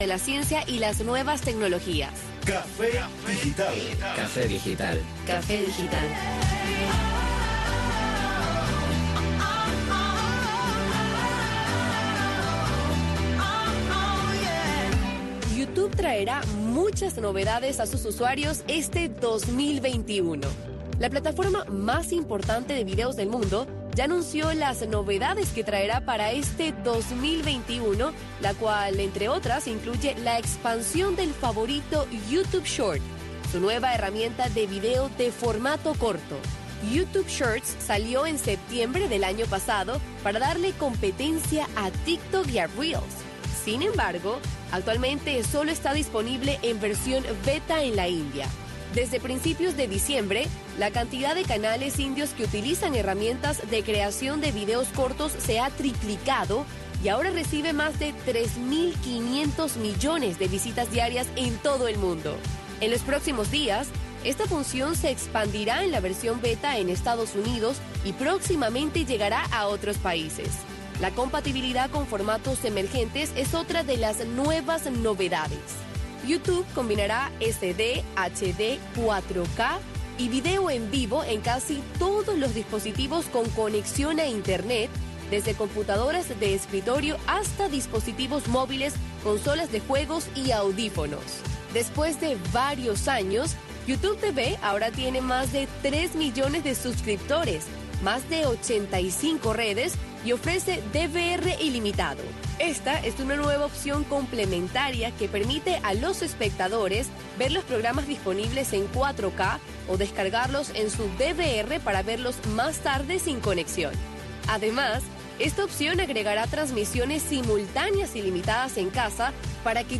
Café digital es un espacio radial que pretende ir más allá del solo objetivo de informar sobre nuevos avances de la ciencia y la tecnología.
Café digital traerá invitados que formen parte de la avanzada científica y tecnológica en el mundo y promoverá iniciativas e ideas que puedan llevar a cabo los jóvenes dentro de Cuba para dar solución a sus necesidades más cotidianas.